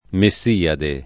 [ me SS& ade ; ted. me SL# adë ]